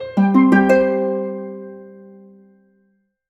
collectable_item_bonus_03.wav